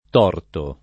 t0rto] part. pass. di torcere, agg., s. m. — es. con acc. scr.: È settentrïonal spada di ladri Tòrta in corona [$ SSettentri-on#l Sp#da di l#dri t0rta ij kor1na] (Giusti) — sim. il top. m. Torto (Sic.) e i cogn. Torti, Del Torto, Lo Torto